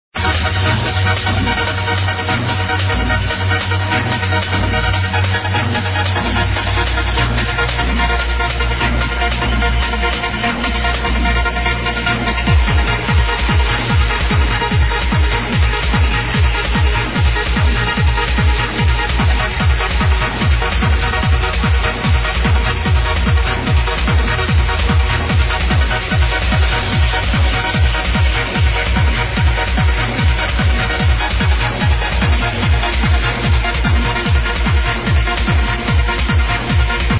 same story ... got from thr radio a very long time ago.
and this one sounds fresher than 94 =)